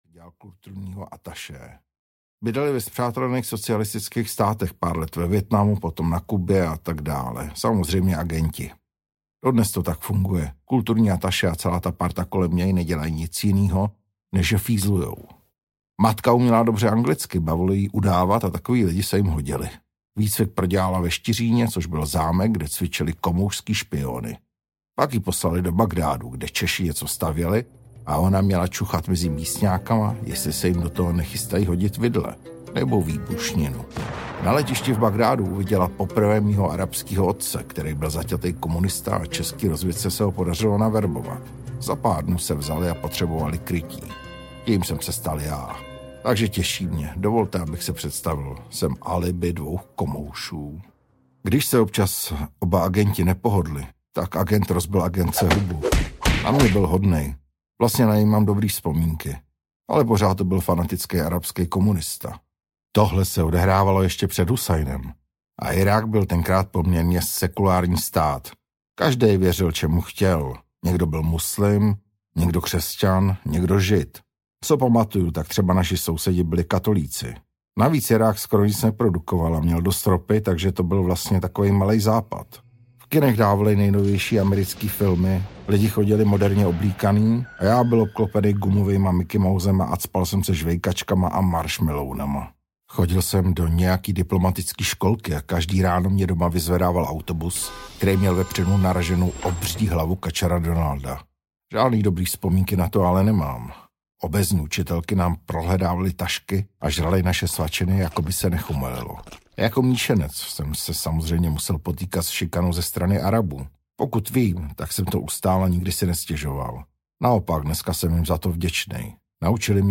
Vyvrhel audiokniha
Ukázka z knihy